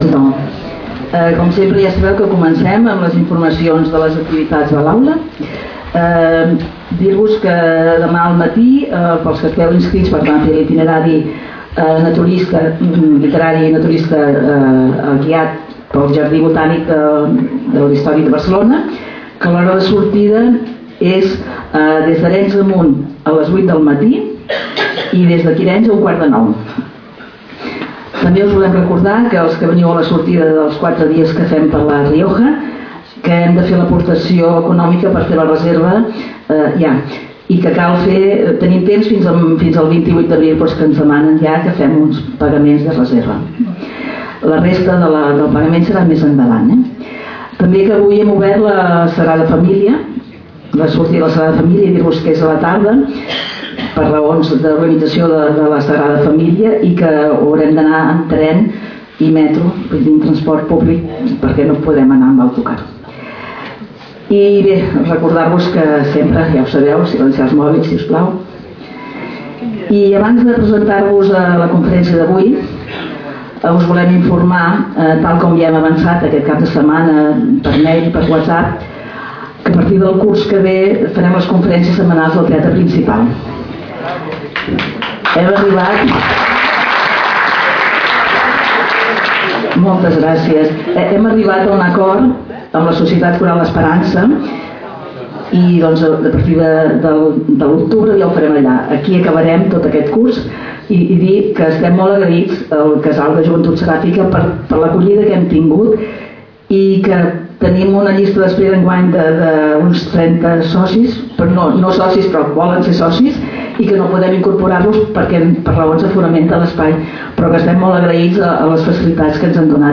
Es costum que a la setmana de Sant Jordi organitzem una conferència que tingui a veure amb la llengua o literatura.
Lloc: Casal de Joventut Seràfica